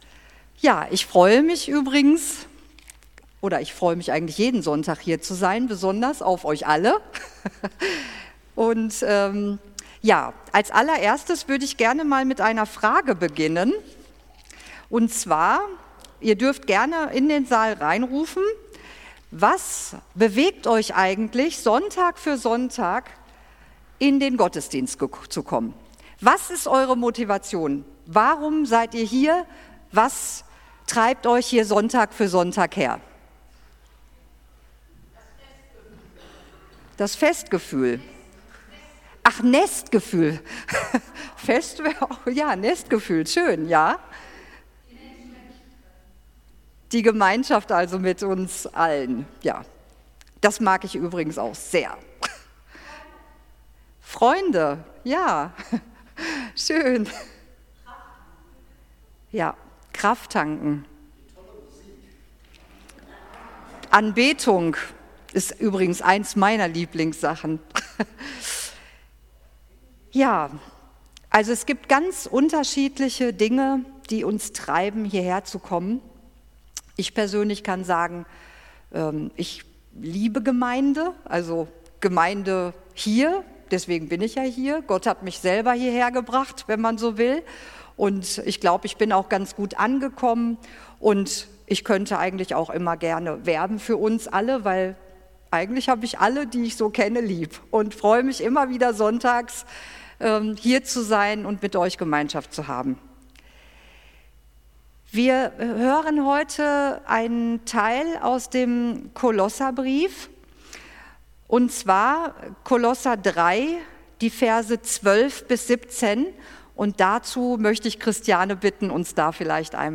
Gottesdienst
Okt. 2, 2024 | Predigten | 0 Kommentare